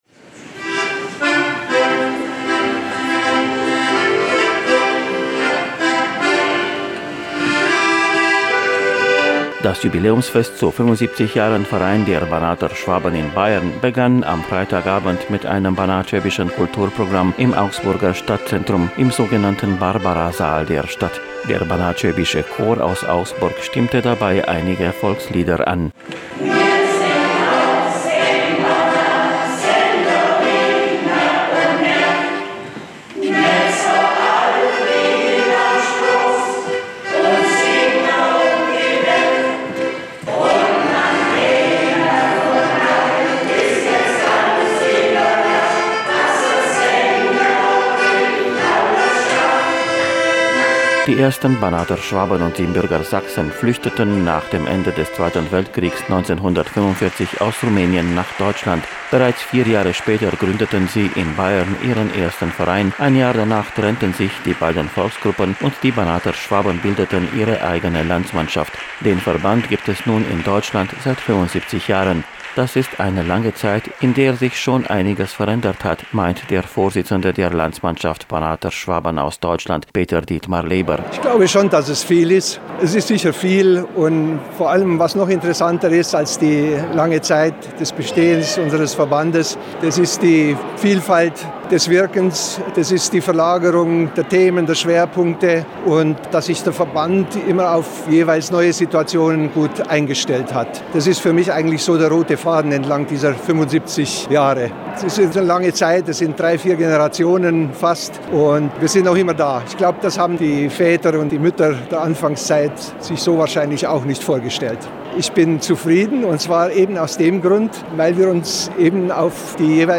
Die Veranstalter nahmen sich vor, die Geschichte und das kulturelle Erbe der Banater Schwaben in Bayern seit ihrer Ankunft nach dem Zweiten Weltkrieg zu würdigen und gleichzeitig vorort ein Zeichen für den gegenwärtigen Fortbestand donauschwäbischer Traditionen zu setzen.